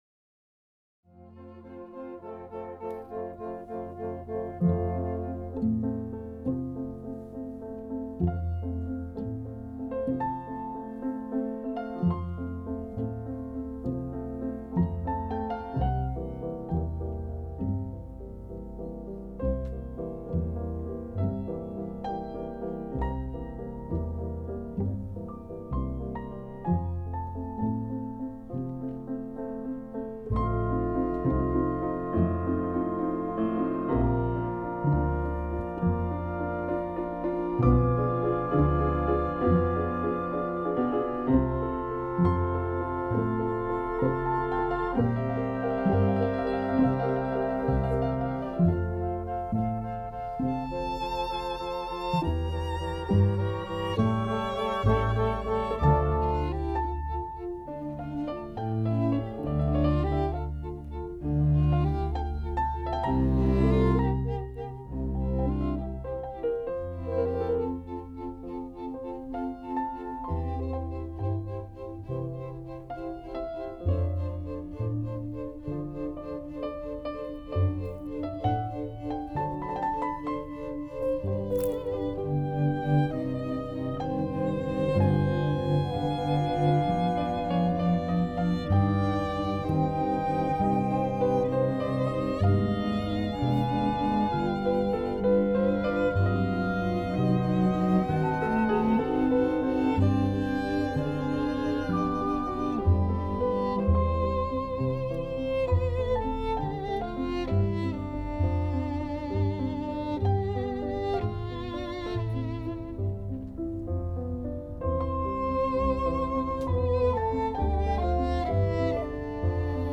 A group of guests sat outdoors as the ensemble played on the patio.
piano
piano-concerto-no.-21-2nd-movement-excerpt.mp3